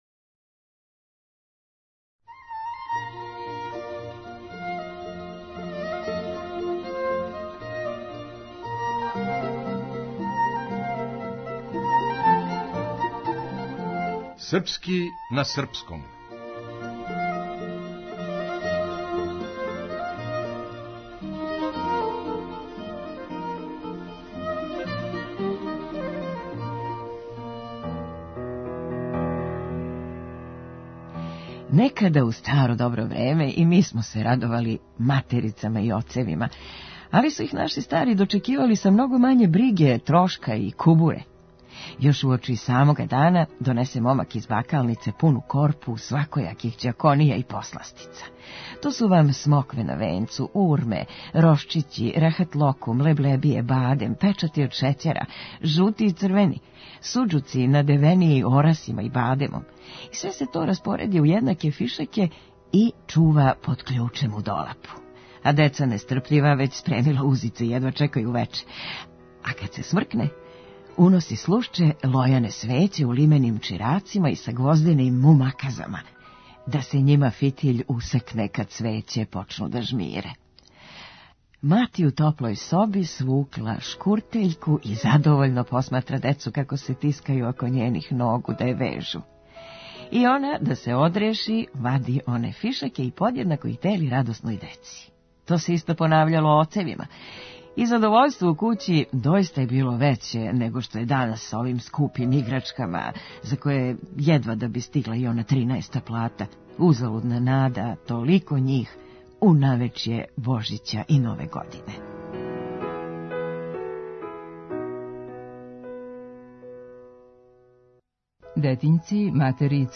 Глумица